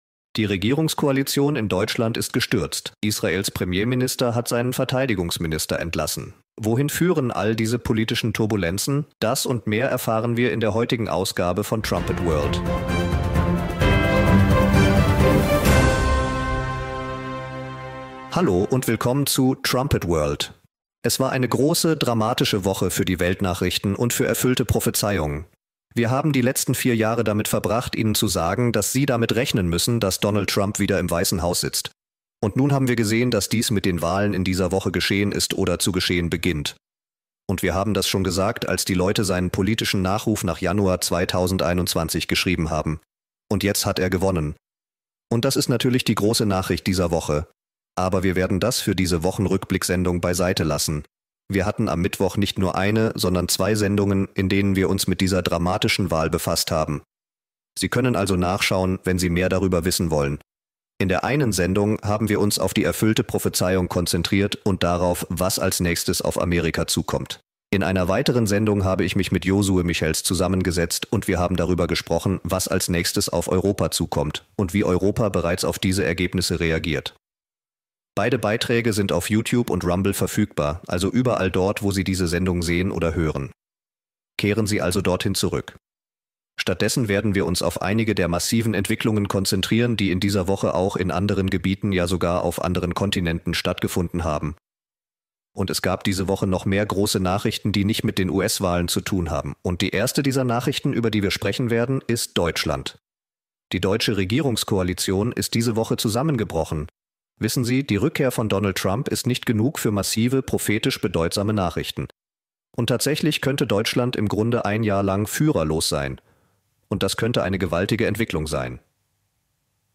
Nehmen Sie an der Diskussion teil, wenn die Mitarbeiter der Posaune die jüngsten Nachrichten mit der biblischen Prophetie vergleichen.